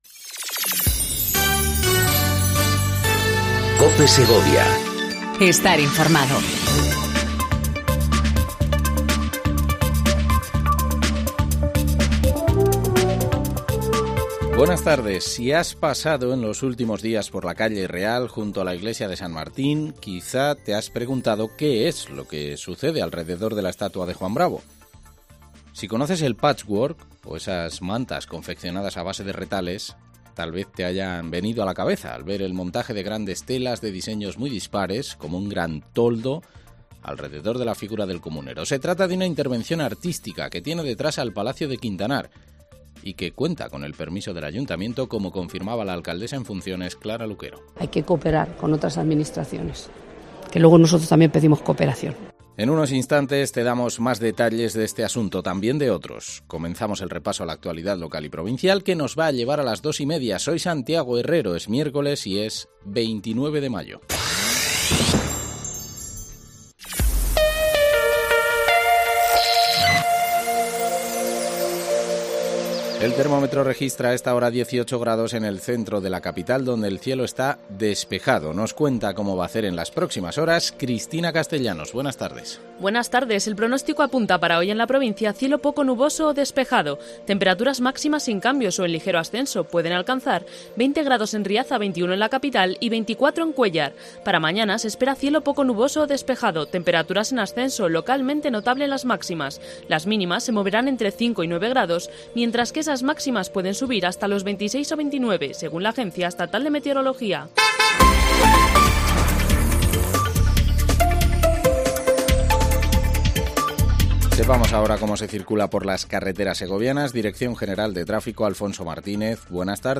INFORMATIVO DEL MEDIODÍA EN COPE SEGOVIA 14:20 DEL 29/05/19